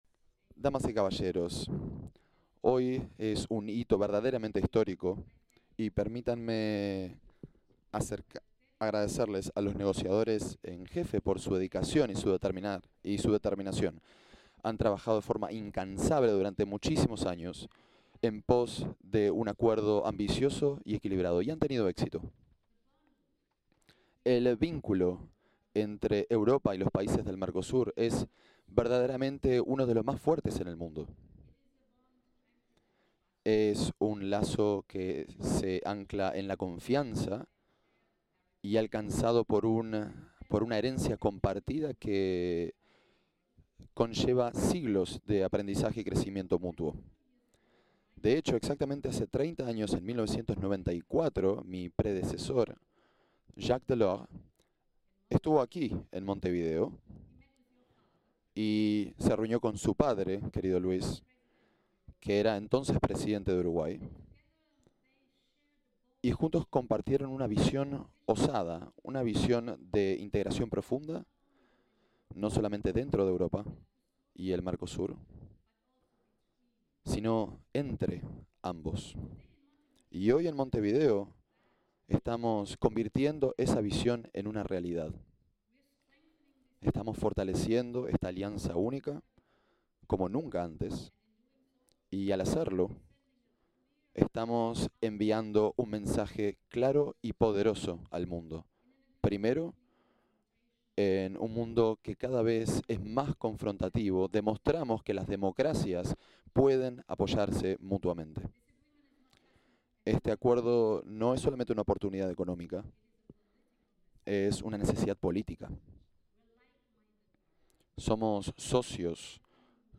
Palabras de la presidenta de la Comisión Europea, Ursula von der Leyen
Palabras de la presidenta de la Comisión Europea, Ursula von der Leyen 06/12/2024 Compartir Facebook X Copiar enlace WhatsApp LinkedIn Este viernes 6, la presidenta de la Comisión Europea, Ursula von der Leyen, participó en una conferencia de prensa, junto a los mandatarios del Mercosur (versión en español).